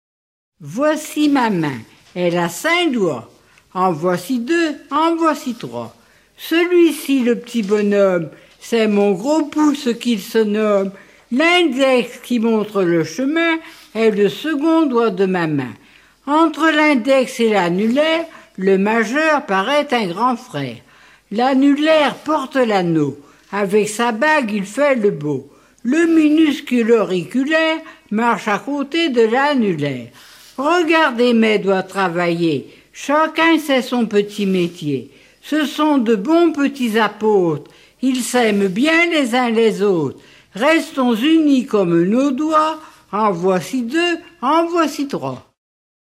formulette enfantine : jeu des doigts